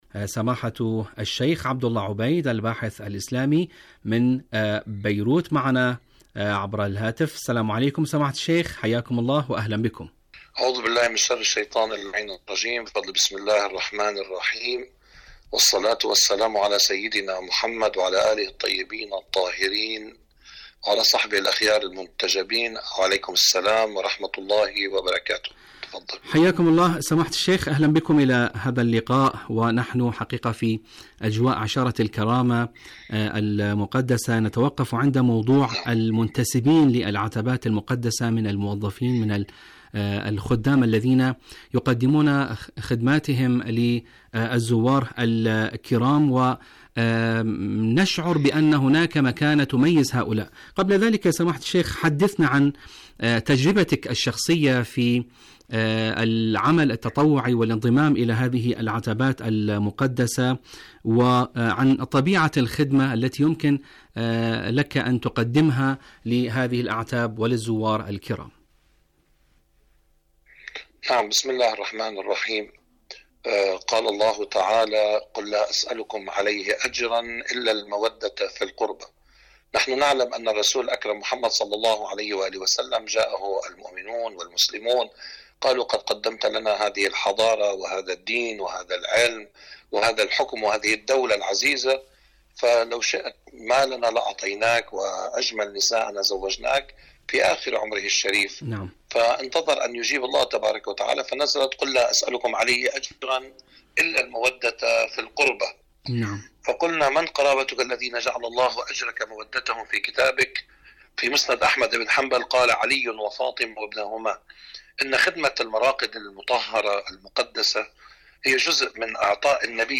مقابلة
إذاعة طهران- دنيا الشباب: مقابلة إذاعية